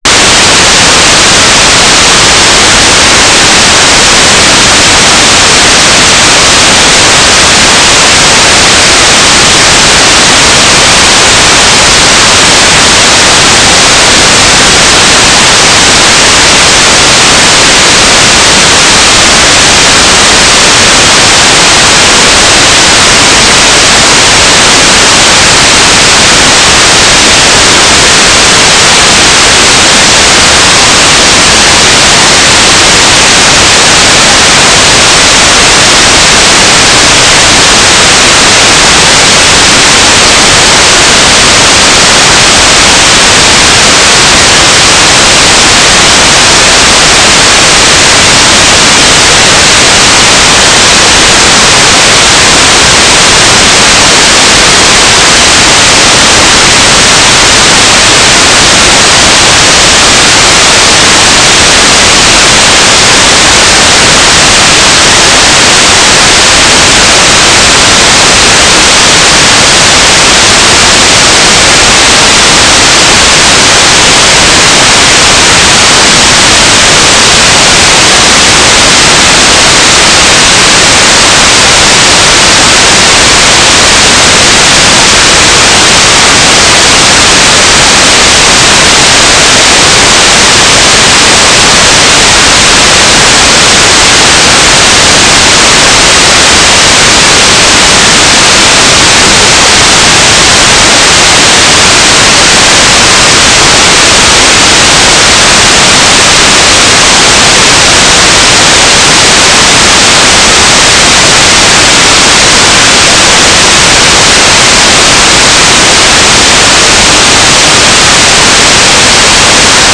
"transmitter_description": "Mode U - GMSK9k6  - AX25 G3RUH - TLM",